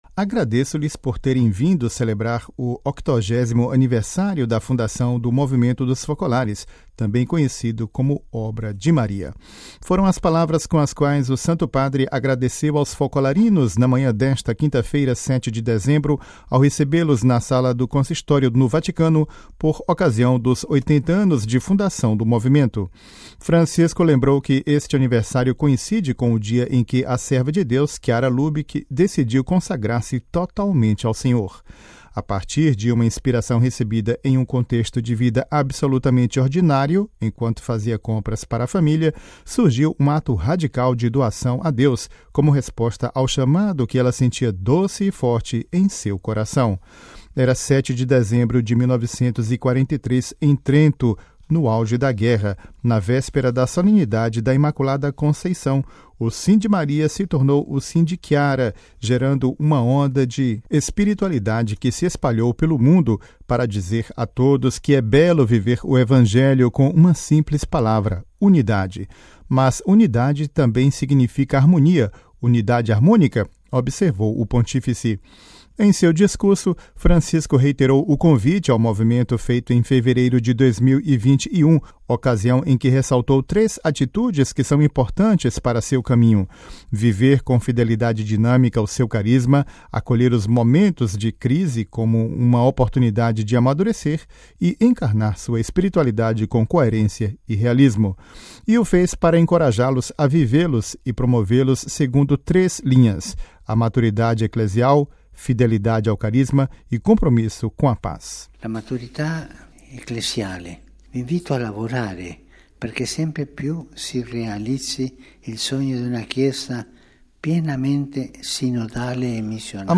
Ouça a reportagem com a voz do Papa Francisco e compartilhe